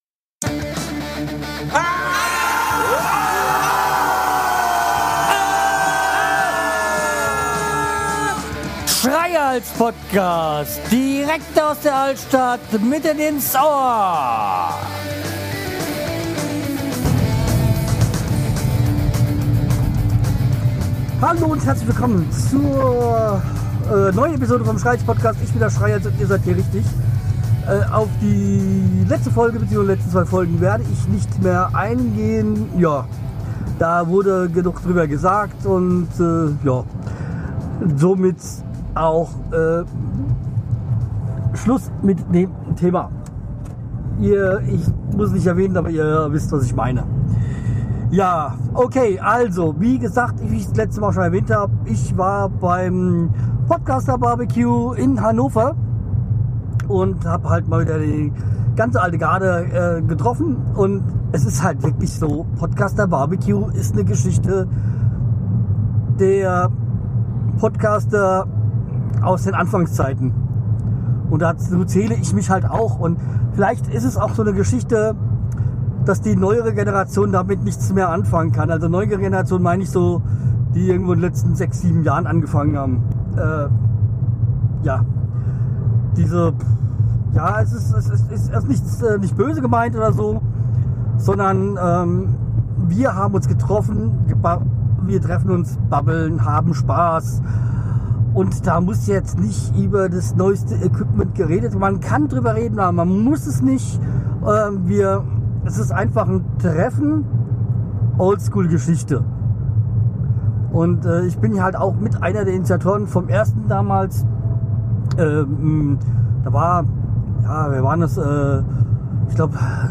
Das Podcaster BBQ in Hannover war mal wieder ein Fest wie man es sich wünscht. In dieser Folge Babel ich im Auto über meine An und Abreise zum PBBQ und wie mir das diesjährige PBBQ gefallen hat.